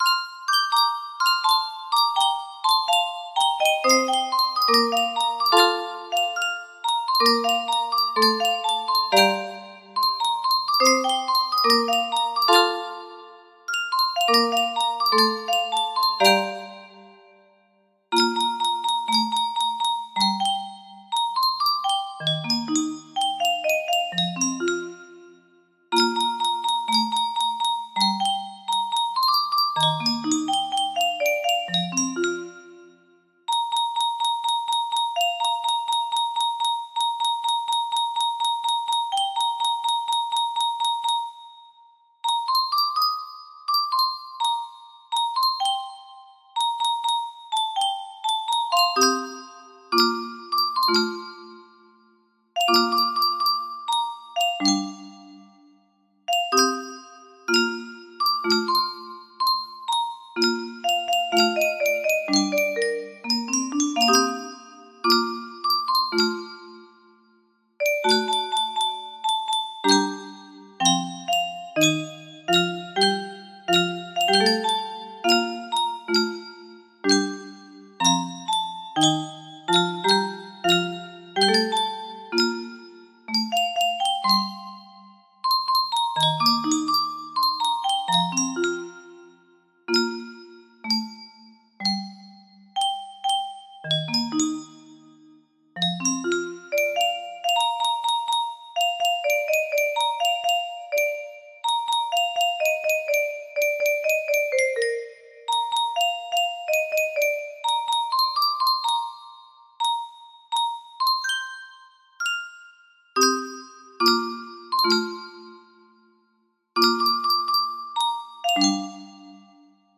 Full range 60
Imported from MIDI Region_1.mid